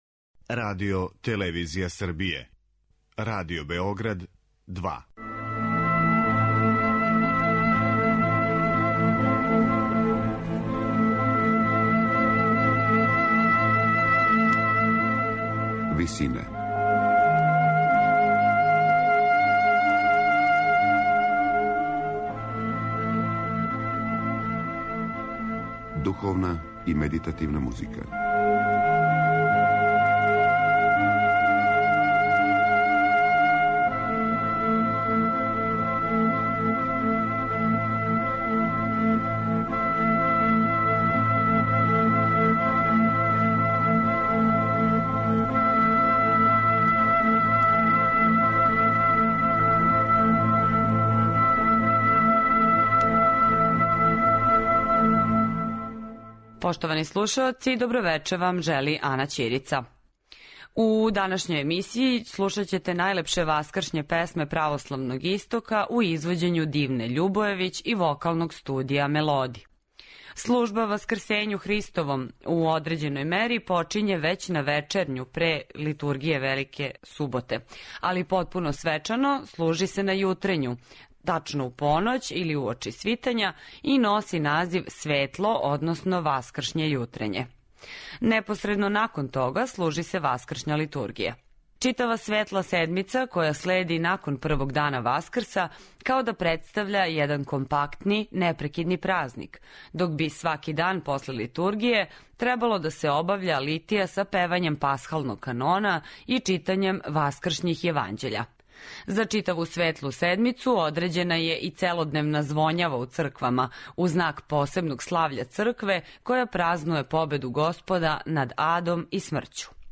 Најлепше васкршње песме православног истока
Трећег дана Васкрса емитујемо напеве различитих аутора у интерпретацији Дивне Љубојевић и Вокалног студија „Мелоди".